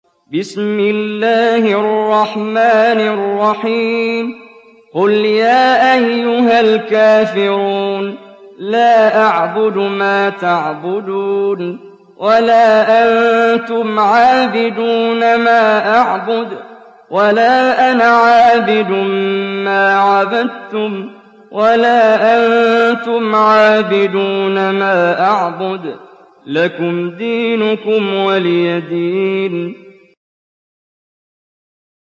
دانلود سوره الكافرون mp3 محمد جبريل (روایت حفص)